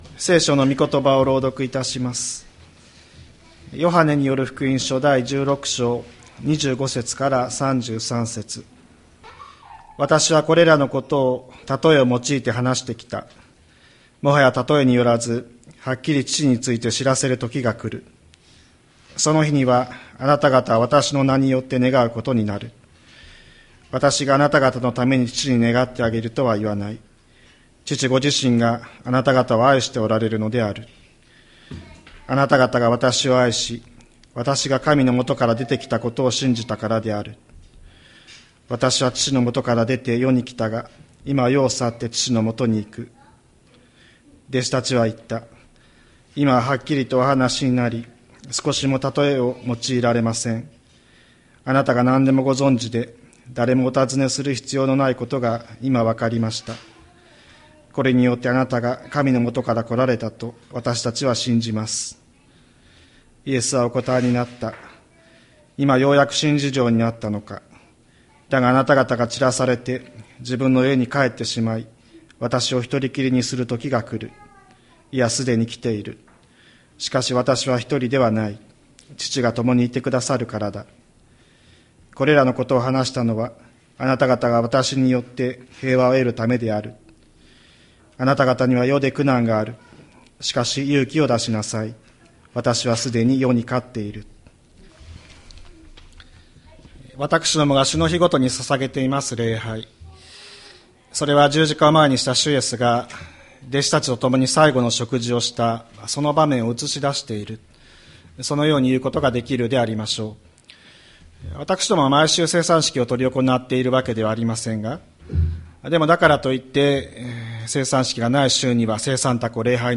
2025年03月16日朝の礼拝「勇気を出しなさい」吹田市千里山のキリスト教会
千里山教会 2025年03月16日の礼拝メッセージ。